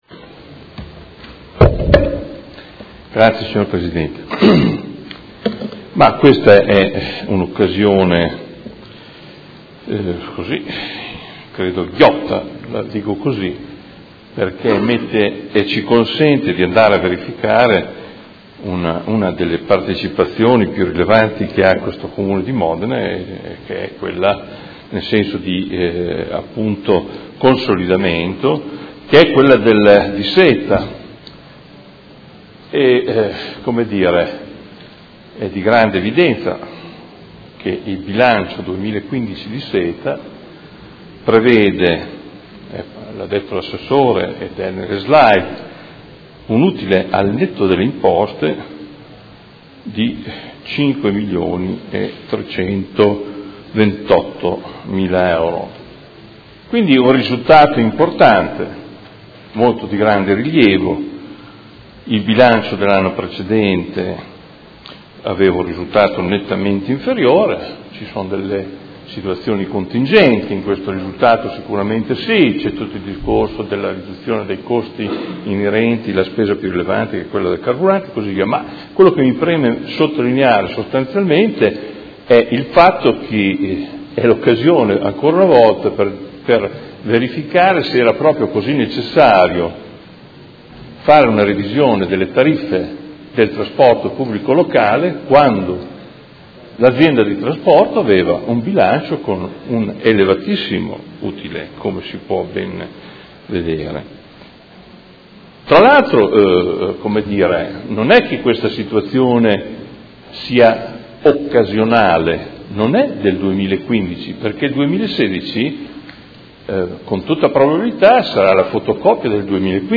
Seduta del 22/09/2016 Proposta di deliberazione: Bilancio Consolidato 2015 del Gruppo Comune di Modena, verifica finale del controllo sulle Società partecipate per l’esercizio 2015 e monitoraggio infrannuale 2016. Dibattito
Audio Consiglio Comunale